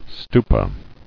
[stu·pa]